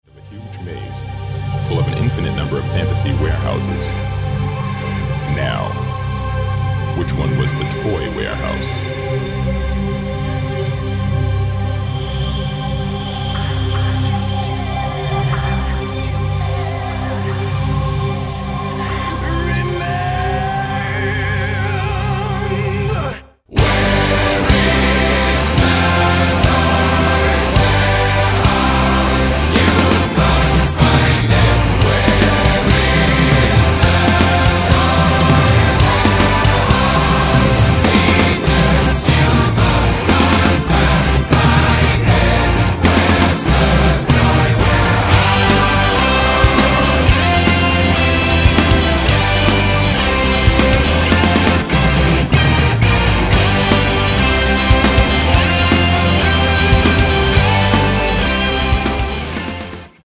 (low quality)